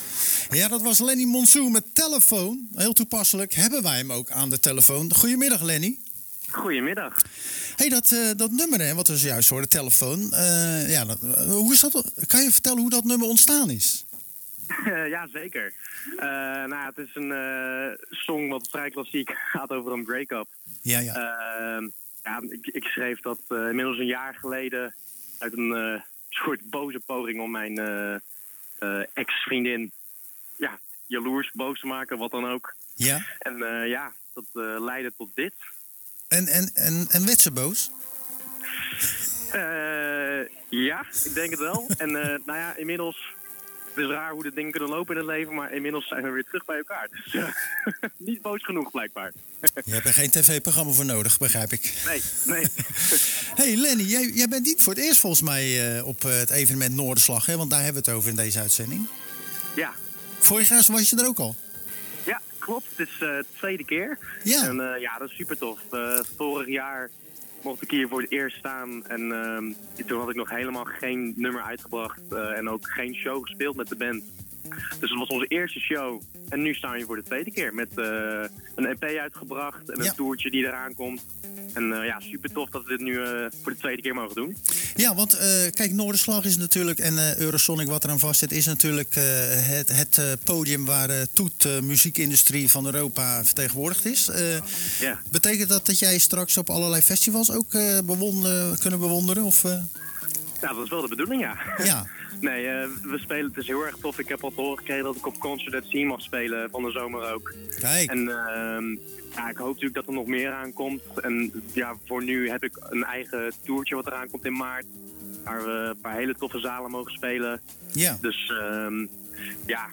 zanger
Tijdens de wekelijkse editie van Zwaardvis belden we met zanger